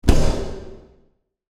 SmokeExplosion 1.ogg